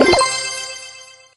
ulti_button_press_01.ogg